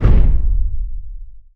metal_robot_large_impact_step_01.wav